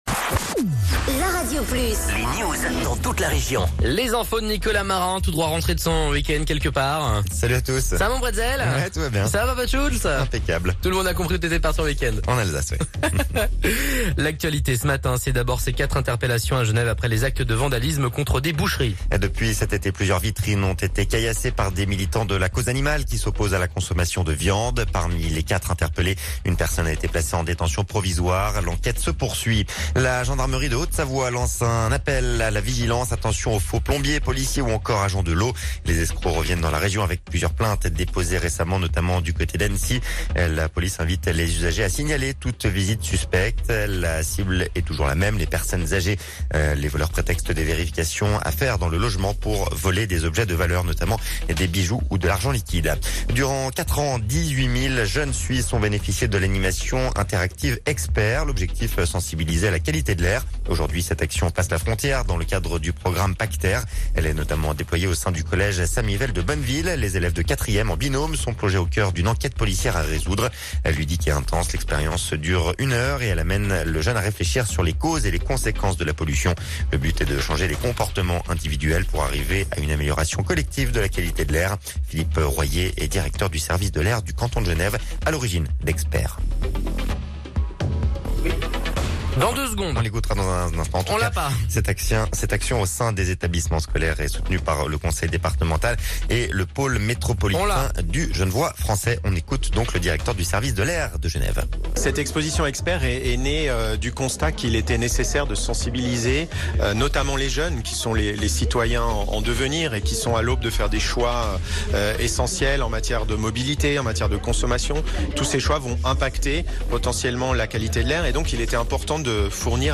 11.12.17 Flash Info 6H
Reportage sur les chiffres de l’observatoire de Haute-Savoie